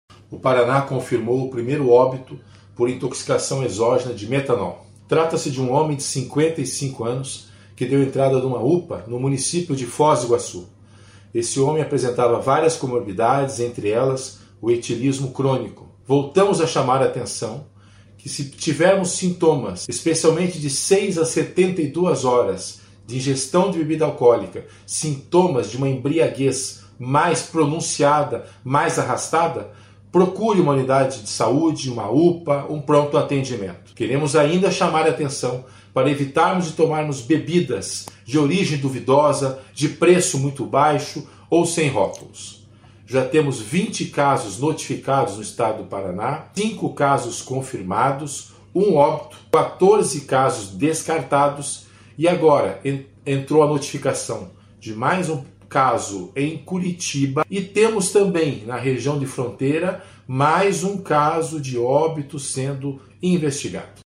Sonora do secretário Estadual da Saúde em exercício, César Neves, sobre a confirmação da primeira morte por intoxicação por metanol no Paraná